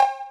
Destroy - RimShotPerc.wav